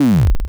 mmo/assets/audio/sfx/death.wav at f21e30eb5594b08e70d50b9c8cef61fe54bc2e3a